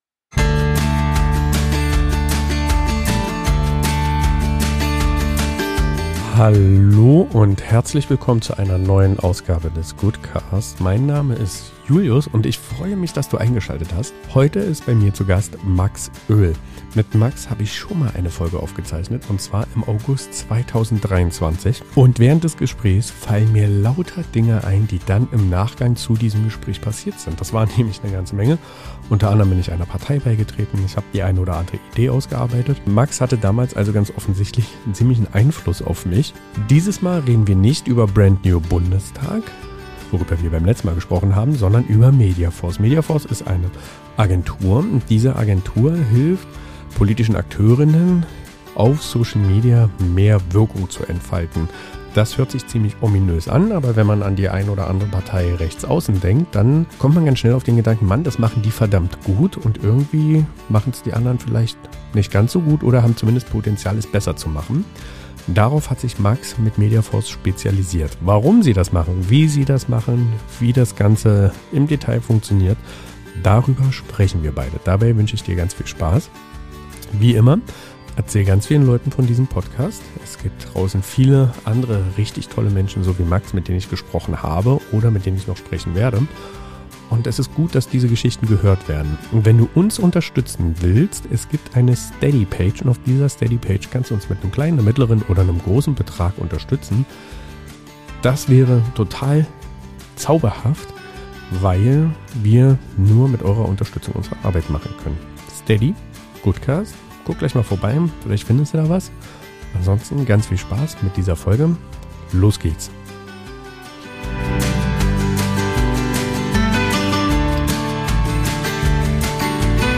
Manche Gespräche fühlen sich sofort vertraut an.